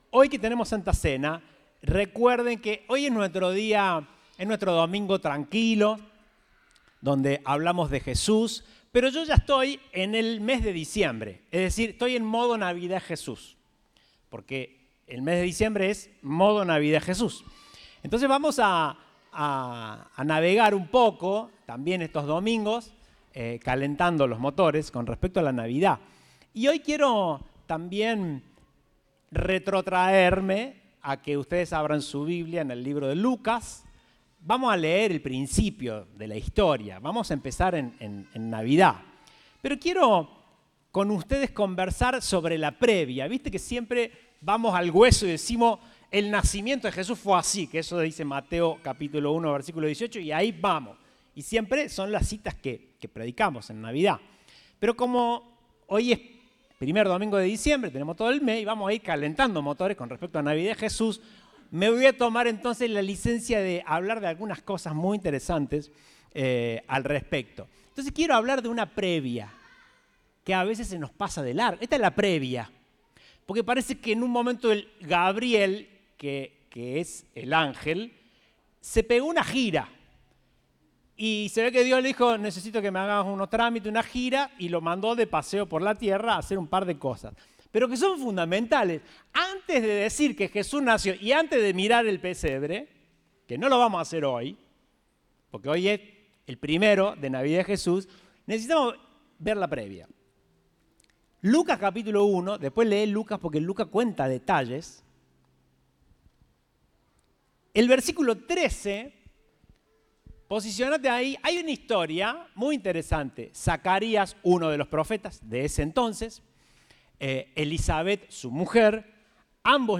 Compartimos el mensaje del Domingo 5 de Diciembre de 2021.